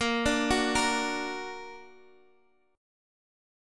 A#+ chord